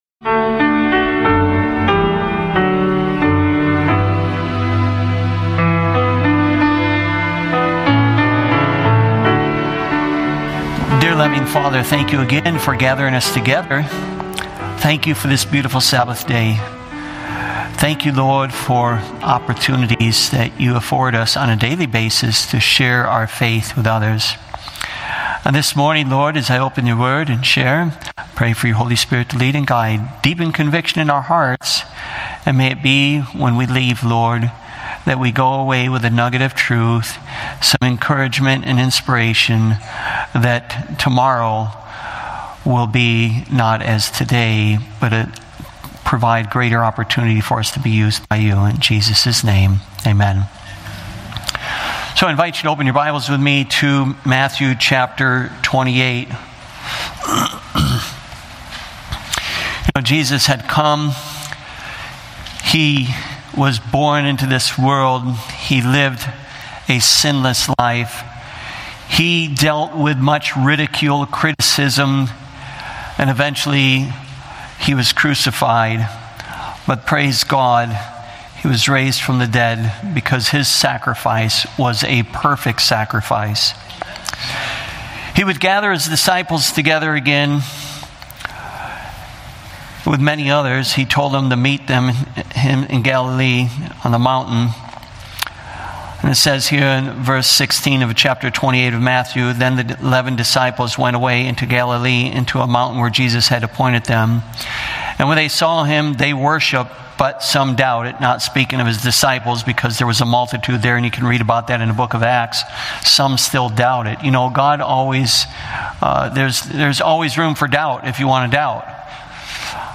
Jesus calls every believer to share the Gospel, no matter their background or experience. This sermon explores the power of divine encounters, biblical parallels of salvation, and the importance of being prepared to share our faith. Through real-life stories and scriptural insights, you'll be encouraged to step out, trust in God's grace, and embrace the universal call to evangelism.